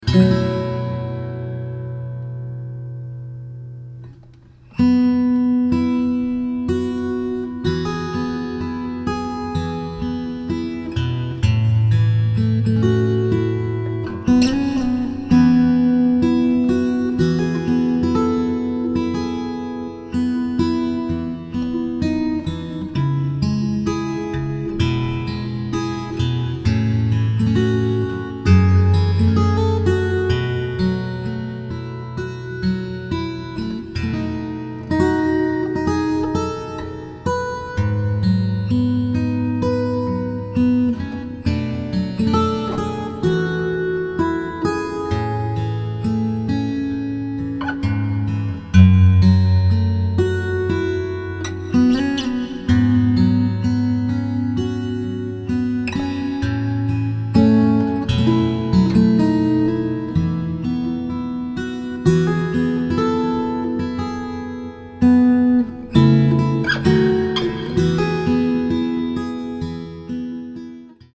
（￣～￣;）ウーン・・・ マイクスタンドを2本立てて，コンデンサーマイクを使って，ベルデンケーブルでつないで，マイクプリアンプが付いていてファンタム電源をマイクに供給できるタイプのレコーダーで，24ビットで録音して，普通のオーディオ編集ソフトではなくやはり，DAWと呼ばれるソフトを使って編集して，ソフトでリバーブをかけて，それを16ビットステレオにミックスダウンしたら・・・・。
これがいい音なのです。
これが本当に自宅録音でしょうかという気持ちになったりします。